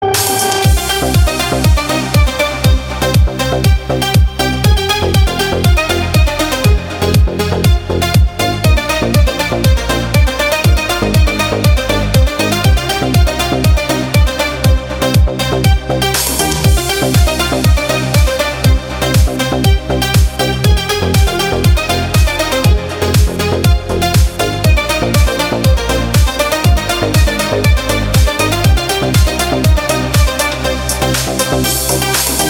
deep house
без слов
энергичные
быстрые
подвижные
Melodic